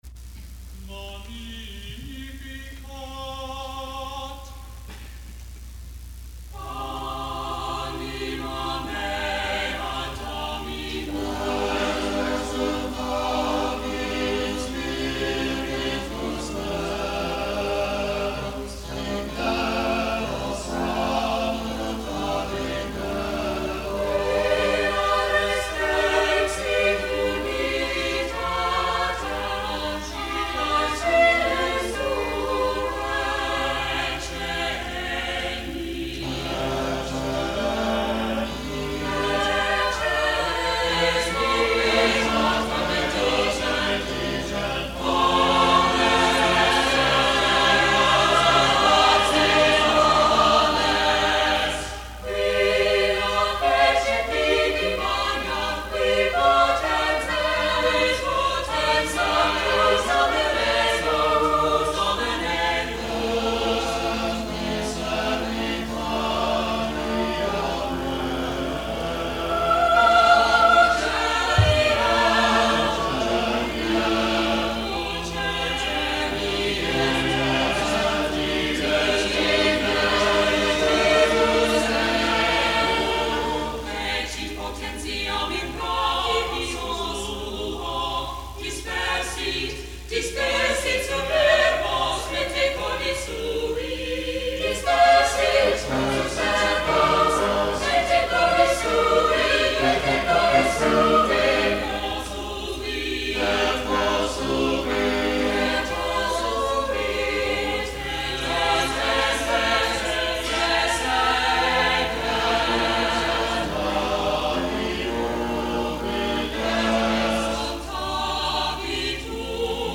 Andrea-Gabrieli-Magnificat-a12.mp3